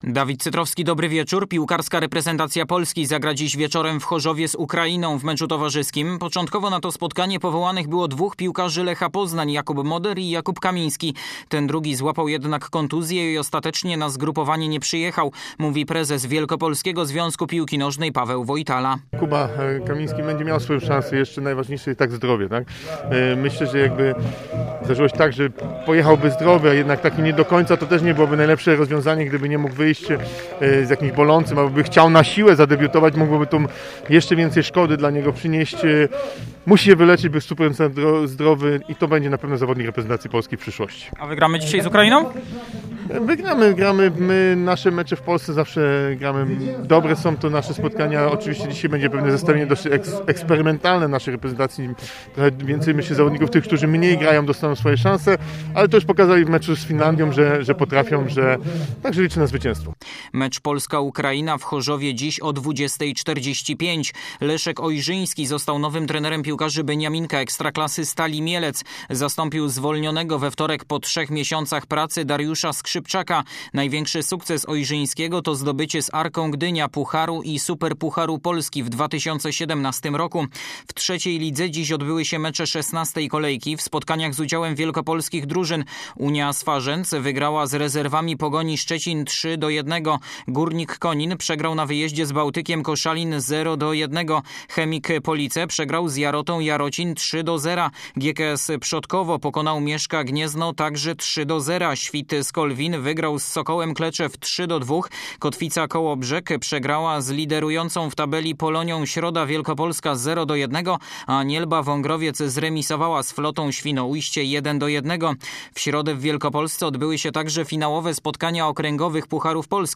11.11. SERWIS SPORTOWY GODZ. 19:05